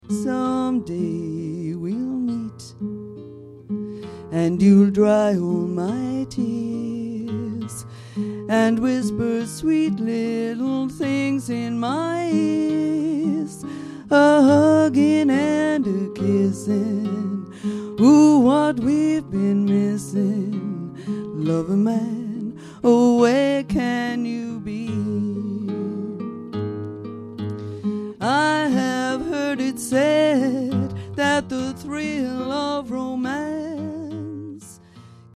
It's all at Ashington Folk Club!